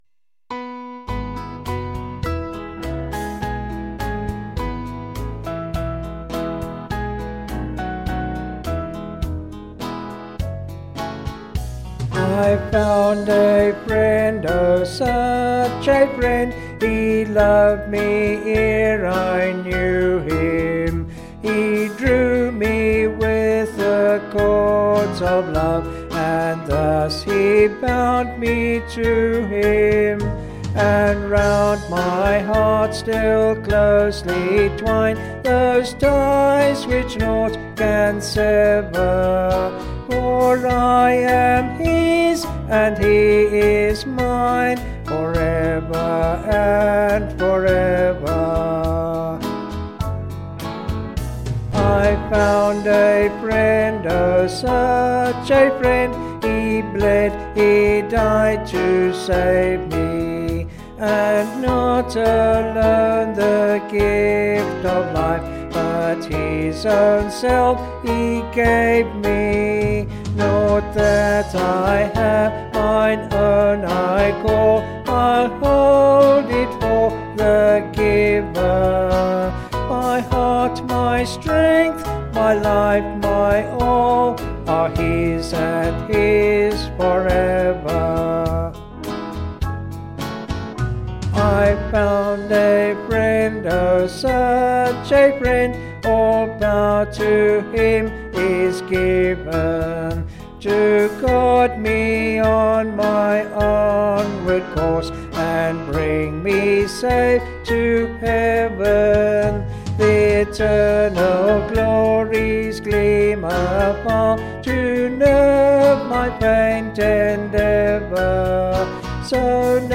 Vocals and Band   265.1kb Sung Lyrics